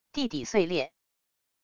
地底碎裂wav音频